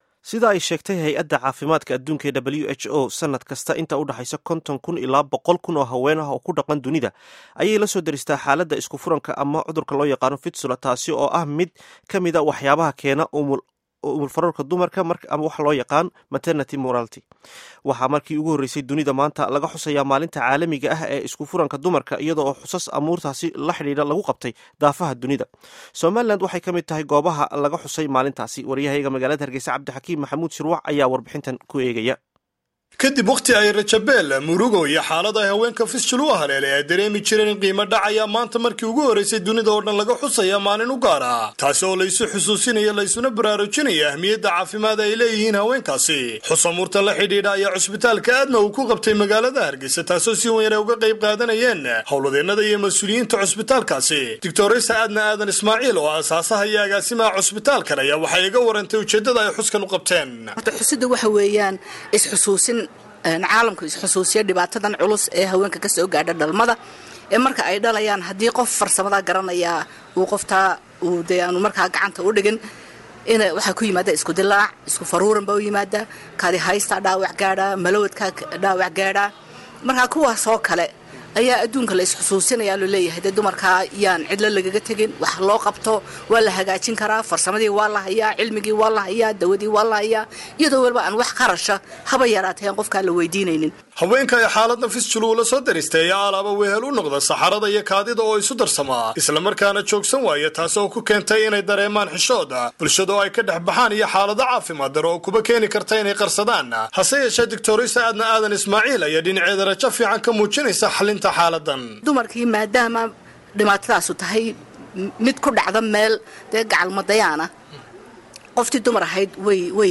Warbixinta Maalinta Xuska Fistula